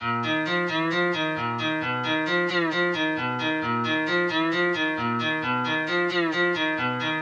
特里普吉它
描述：带效果的Trippy吉他循环。
Tag: 133 bpm Trap Loops Guitar Acoustic Loops 1.21 MB wav Key : D